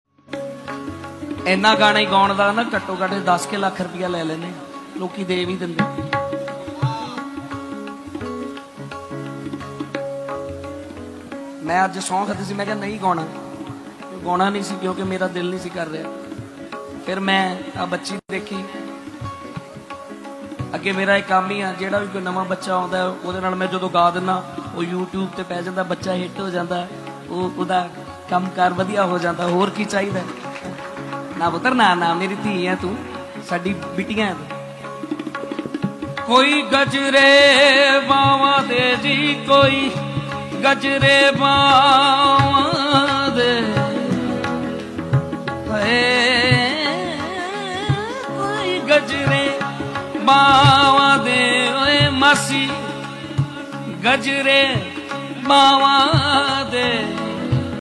live singing #203EB